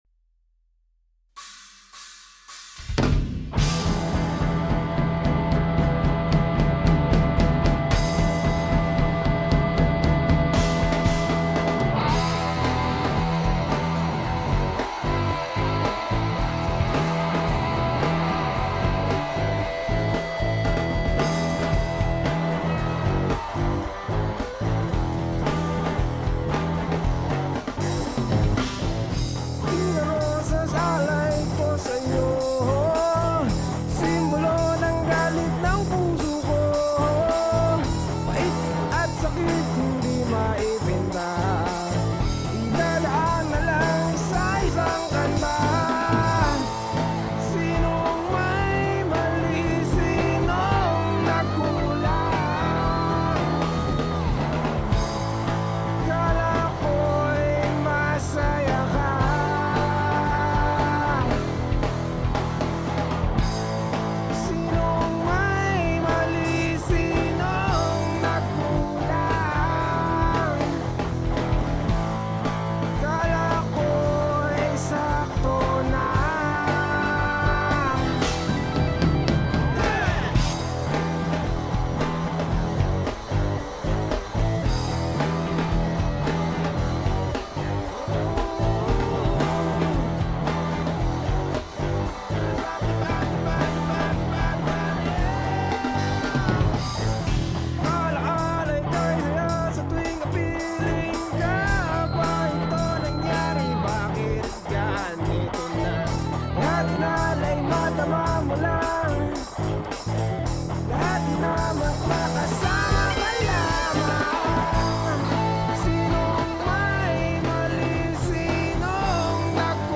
It is poorly recorded so I need comments on restoration. Plus I need help in levels, compression and especially EQs.